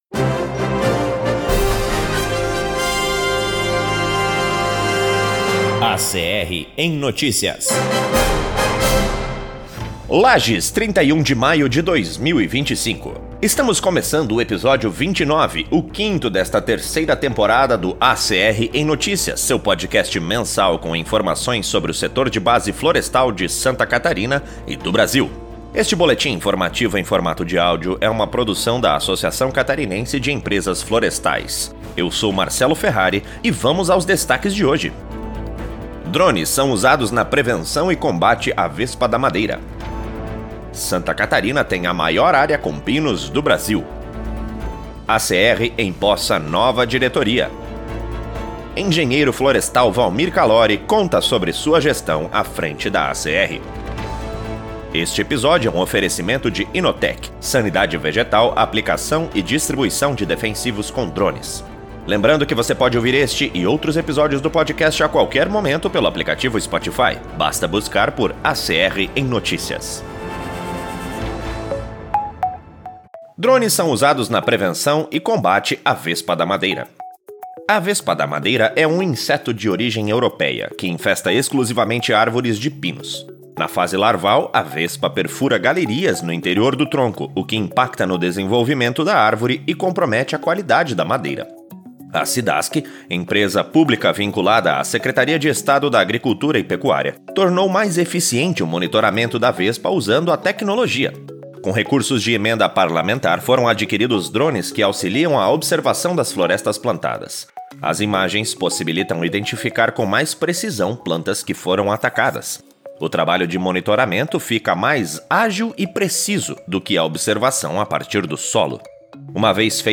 Este boletim informativo em formato de áudio é uma produção da Associação Catarinense de Empresas Florestais.